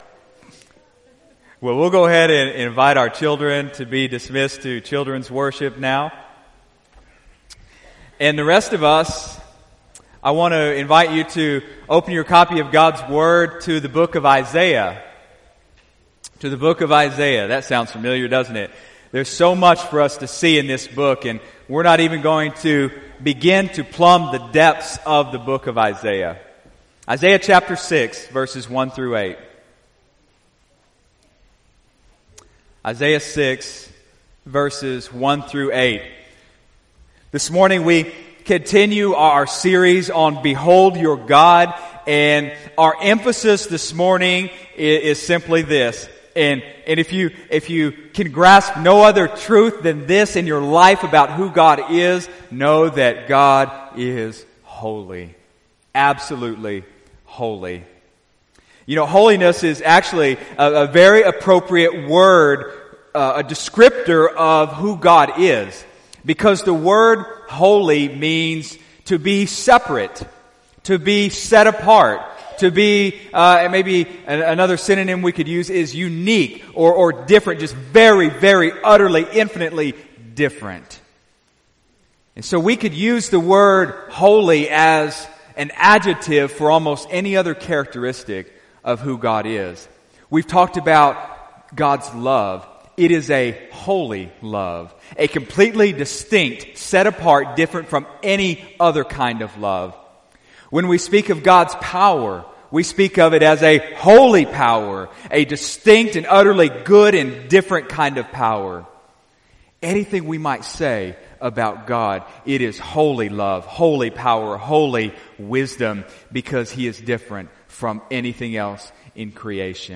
Behold Your God | Sermon Series | Dallasburg Baptist Church
February 25, 2018 (Sunday Morning Service)